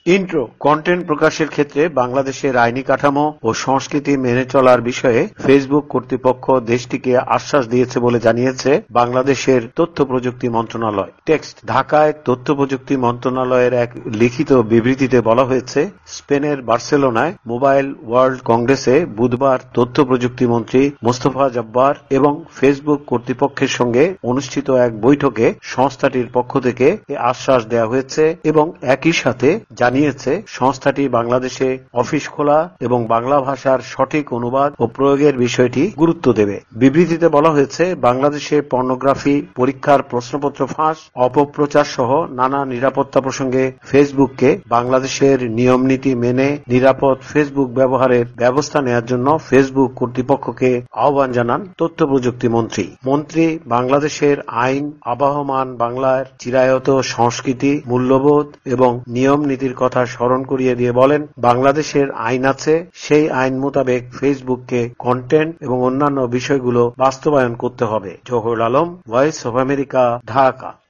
ঢাকা থেকে
প্রতিবেদন